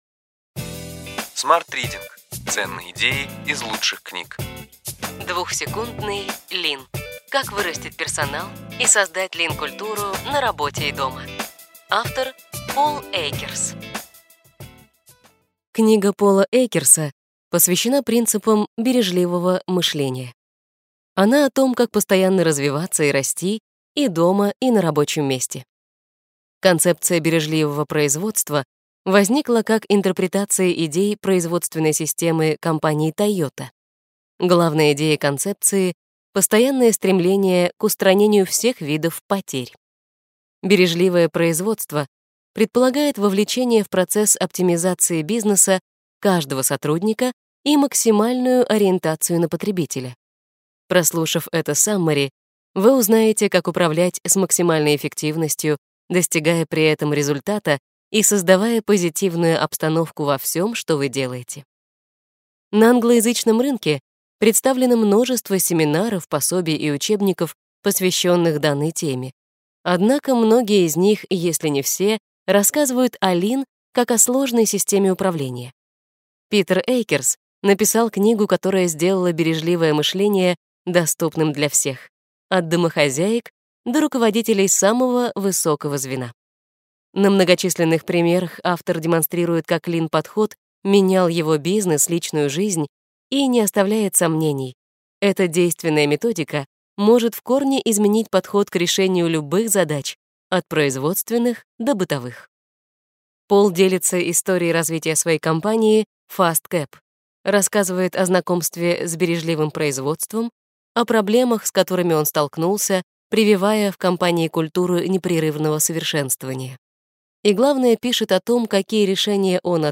Аудиокнига Ключевые идеи книги: Двухсекундный ЛИН: как вырастить персонал и создать ЛИН-культуру на работе и дома.